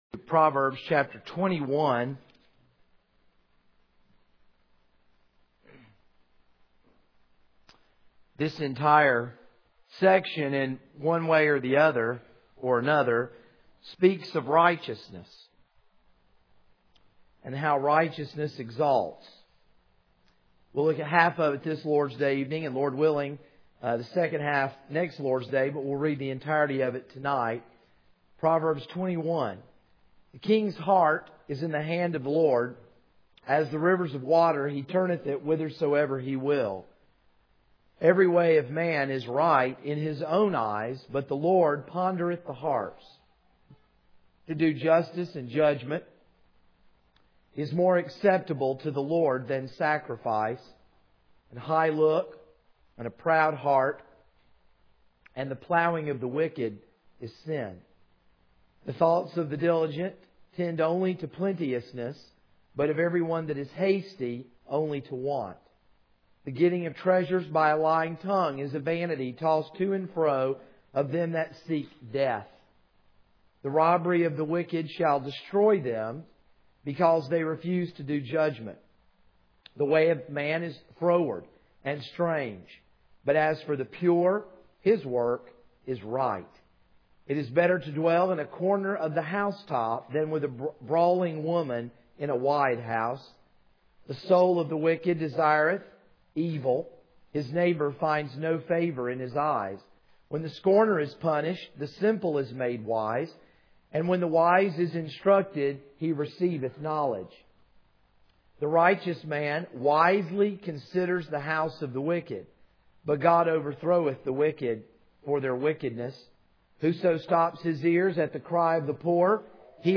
This is a sermon on Proverbs 21:1-18.